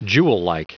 Prononciation du mot jewellike en anglais (fichier audio)
Prononciation du mot : jewellike